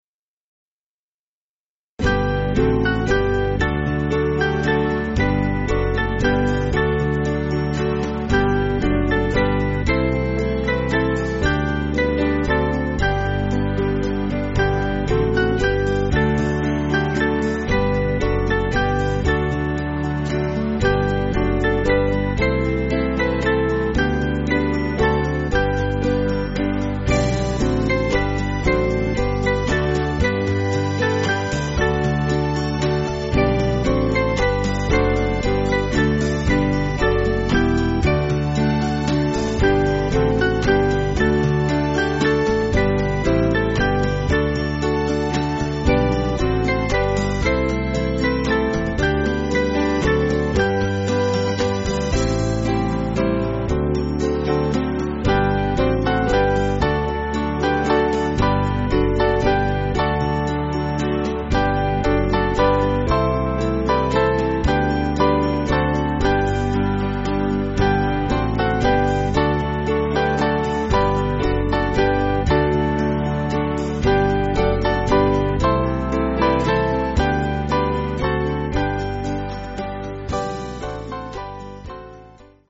Small Band
(CM)   4/G